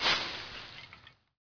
cutgrass.wav